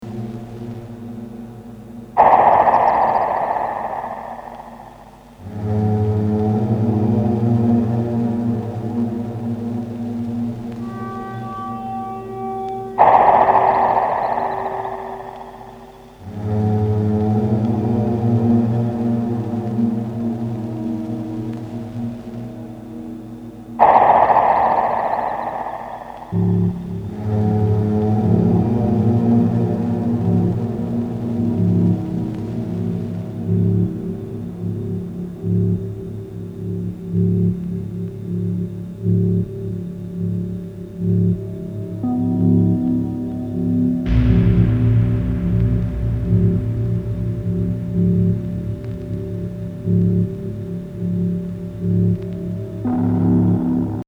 Electronix House Techno